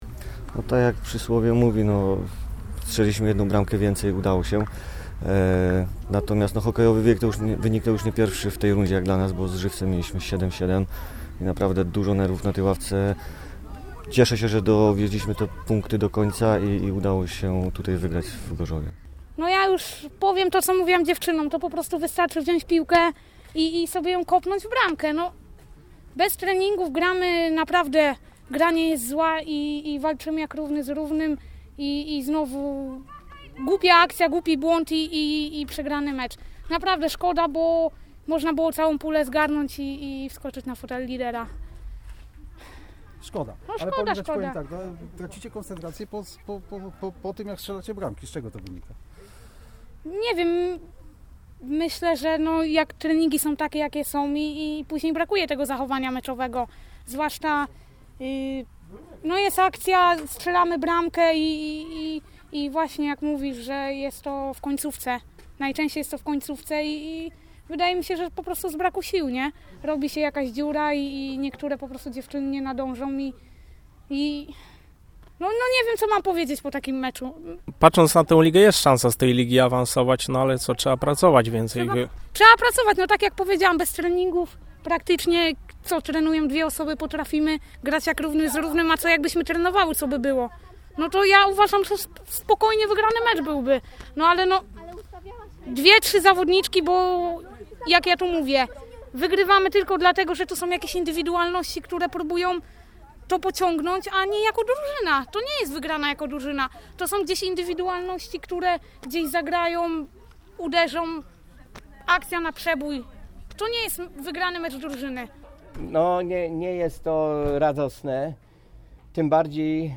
Wypowiedzi